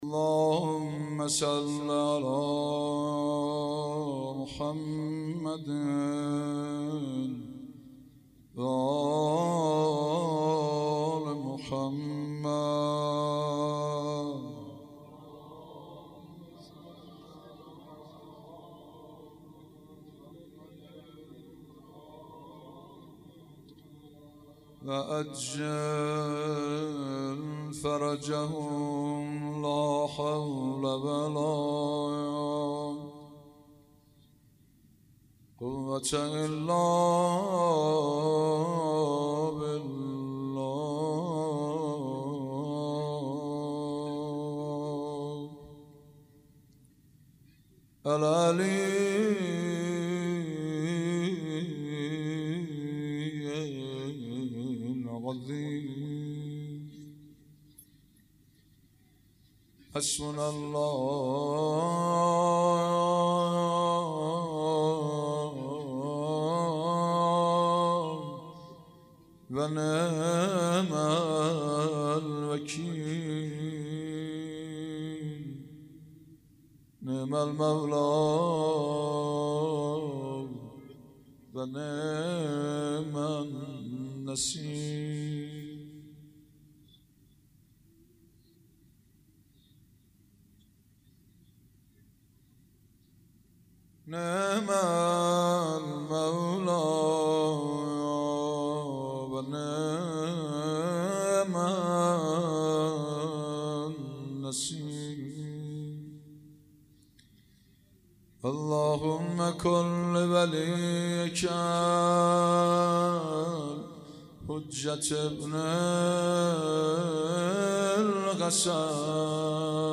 روضه و مناجات
مناسبت : شب دهم رمضان - وفات حضرت خدیجه
قالب : روضه مناجات